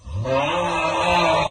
snore-3.ogg